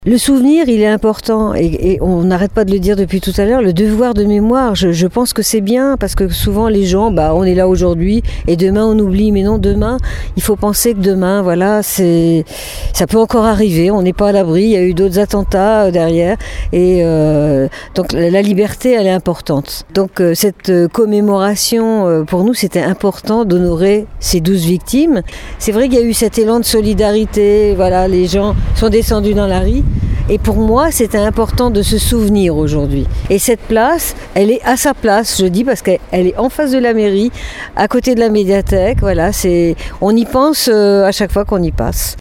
Une cinquantaine de personnes se sont rassemblées hier midi sur la place « Nous sommes Charlie » à La Tremblade, pour un hommage à l’occasion du 10e anniversaire de l’attentat perpétré au siège parisien du journal satirique Charlie Hebdo.
Précédemment, la maire de la commune Laurence Osta-Amigo a honoré la mémoire des victimes, car il est important de ne pas oublier.